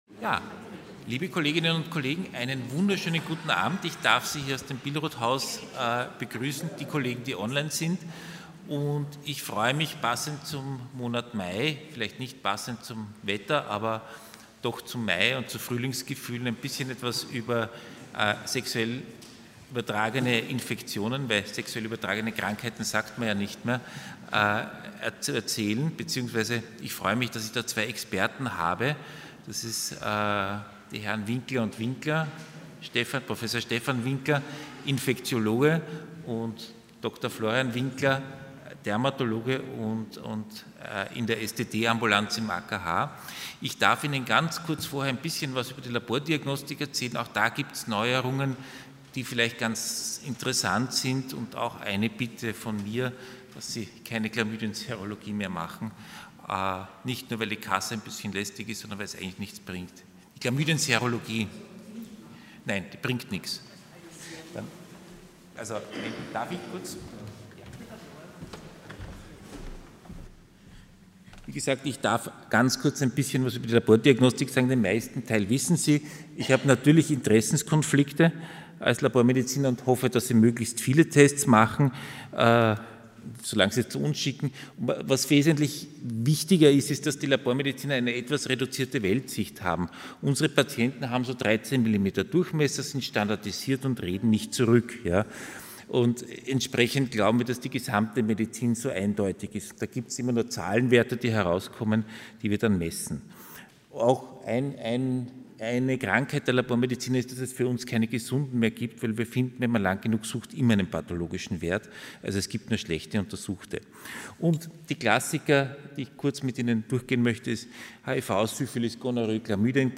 Hybridveranstaltung
Lecture